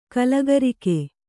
♪ kalagarike